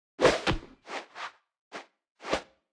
Media:Sfx_Anim_Super_Shelly.wavMedia:Sfx_Anim_Ultra_Shelly.wav 动作音效 anim 在广场点击初级、经典、高手和顶尖形态或者查看其技能时触发动作的音效
Sfx_Anim_Super_Shelly.wav